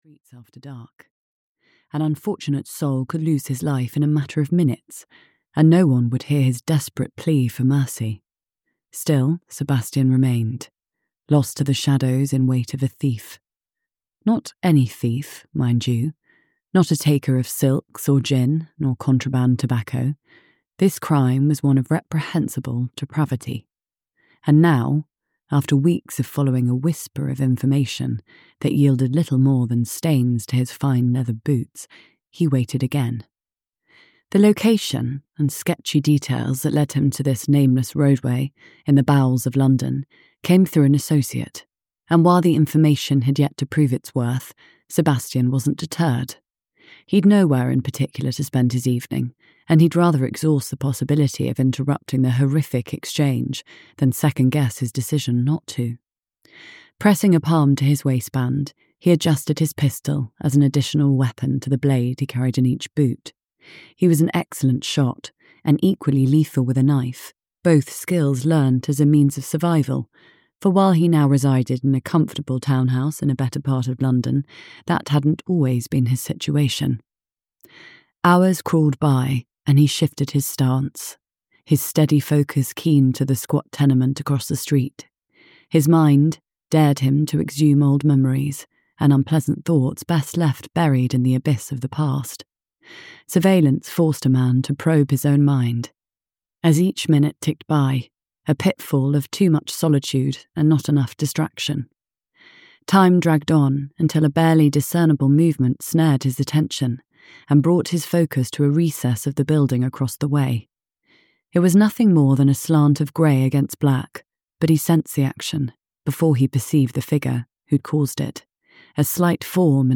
The Lady Loves Danger (EN) audiokniha
Ukázka z knihy